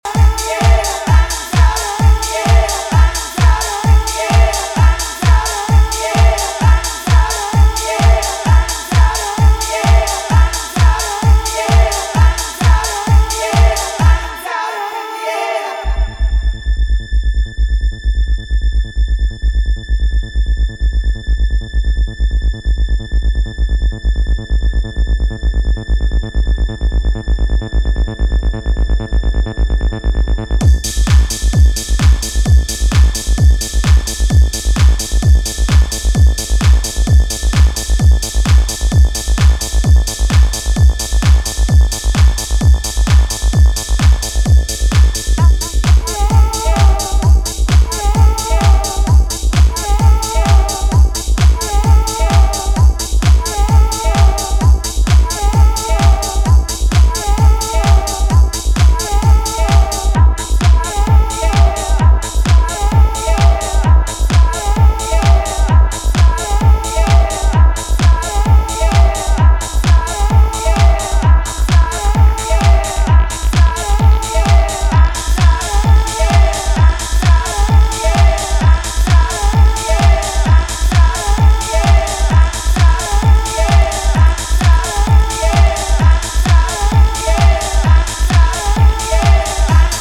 疾走するアシッド・ハウス